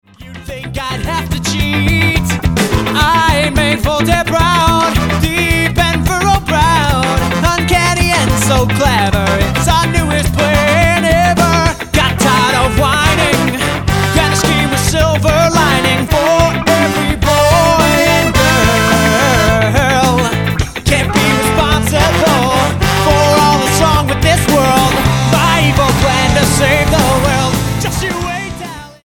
ska band